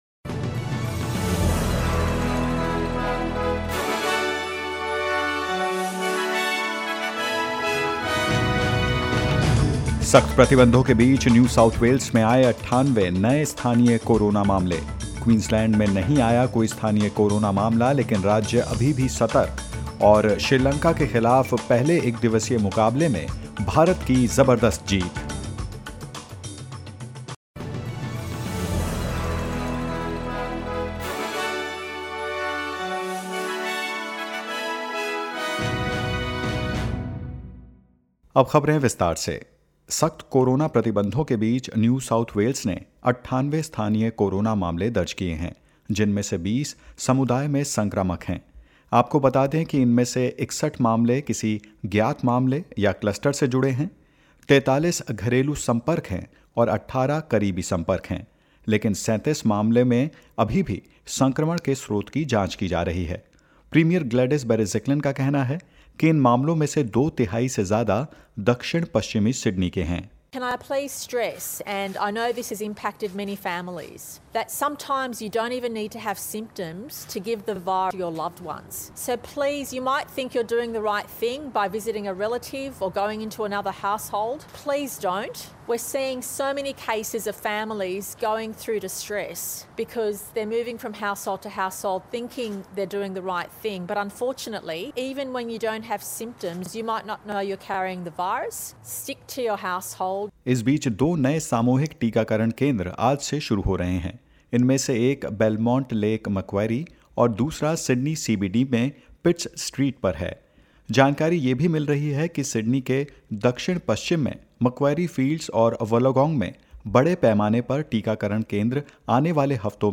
In this latest SBS Hindi News bulletin of Australia and India: Victoria lockdown to be extended as state records 16 new cases; India wins first ODI against Sri Lanka by 7 wickets and more.